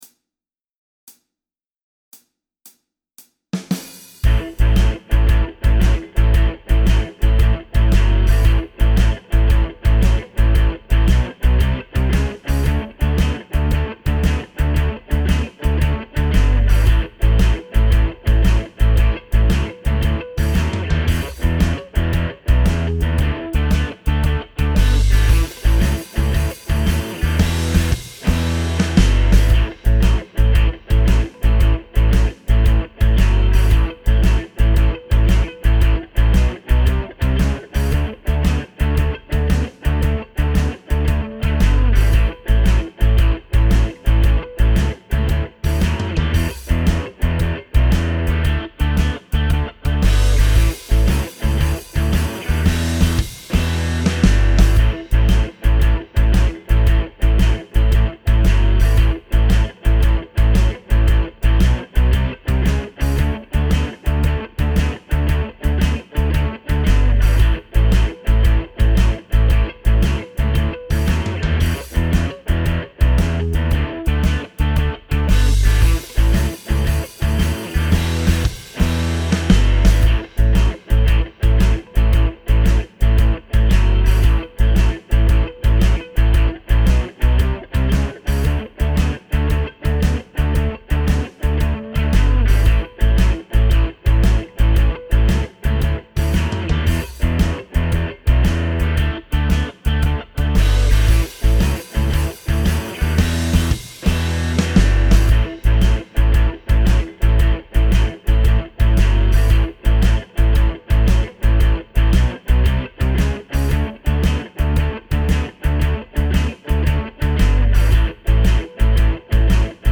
【DAY2】シャッフルブルース編
ギターを楽しむための「レシピ」を解説したレッスン動画サイトです。